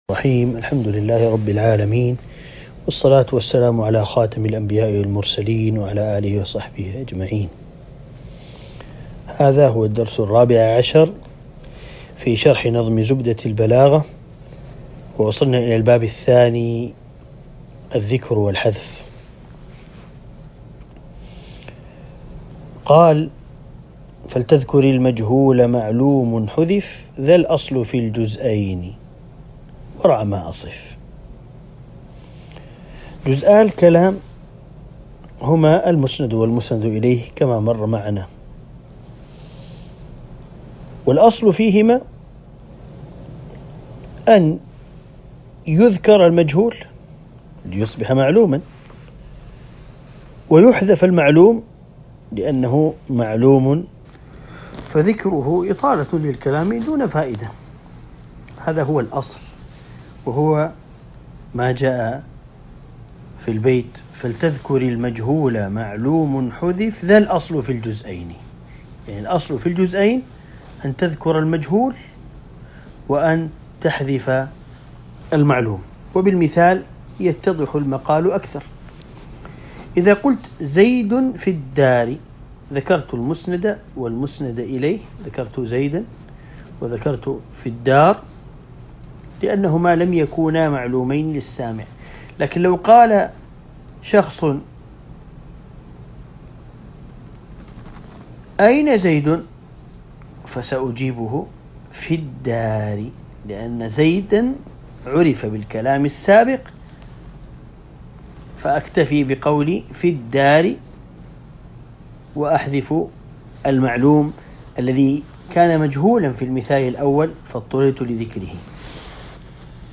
الدرس ( 14) شرح نظم زبدة البلاغة